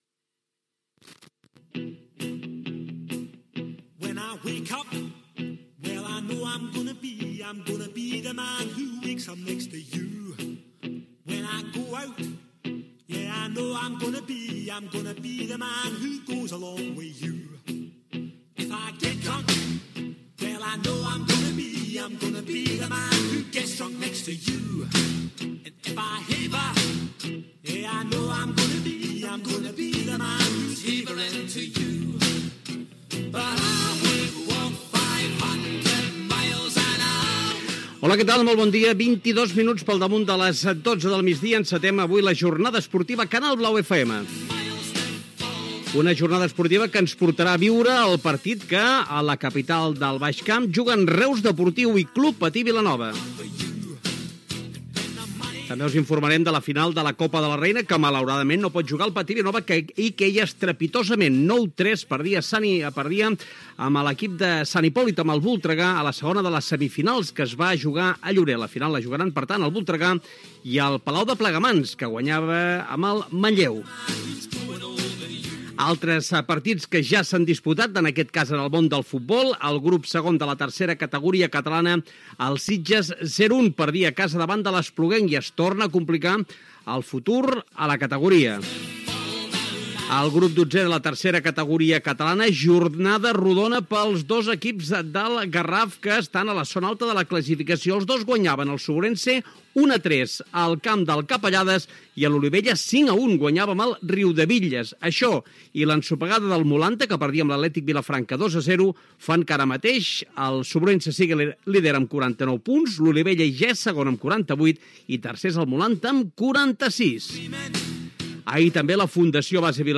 Identificació del programa, resultats de la jornada dels equips locals, connexió amb el partit Reus Deportiu-Club Patí Vilanova.
Esportiu